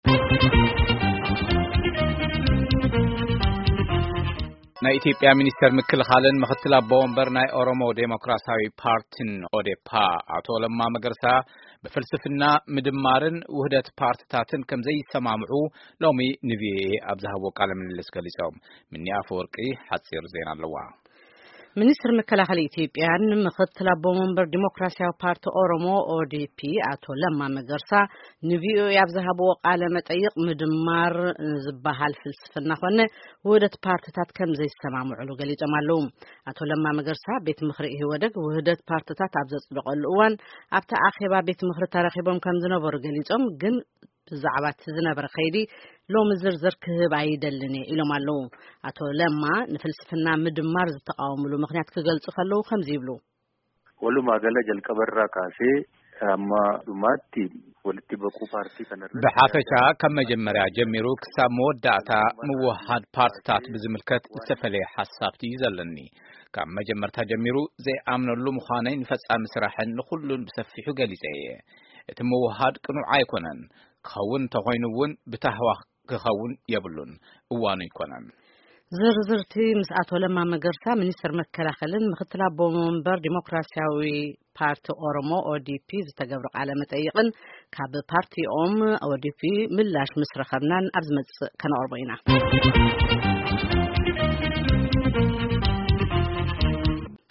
ንሶም ሎሚ ምስ ድምጺ ኣሜሪካ ኣብ ዘካየድዎ ቃለ መጠይቅ ቤት ምኽሪ ኢህወዴግ ውህደት ፓርቲታት ኣብ ዘጽደቐሉ ጊዜ ኣብቲ አኼባ ተረኺቦም ከምዝነበሩን ግን ብዛዕባ’ቲ ዝነብረ ከይዲ ዝርዝር ሓበሬታ ንምሃብ ፍቓደኛ ከምዘይኮኑ ገሊጾም ።